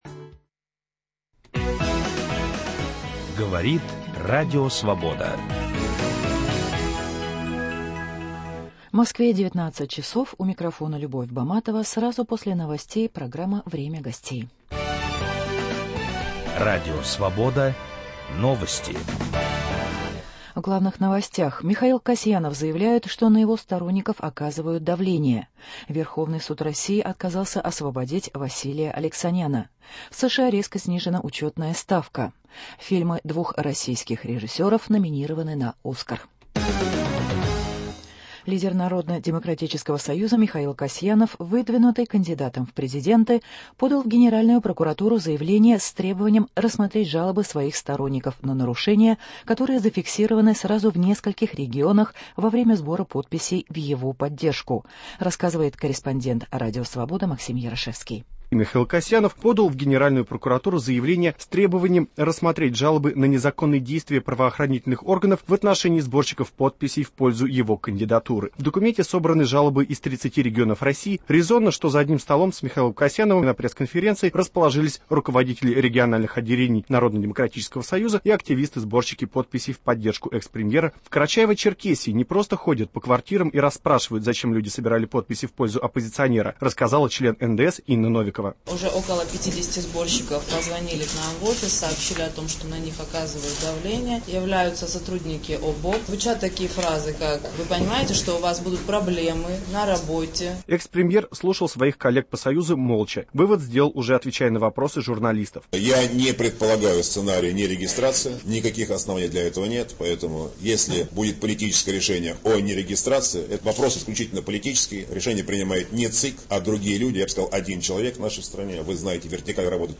В программе выступит политик и историк Владмир Рыжков.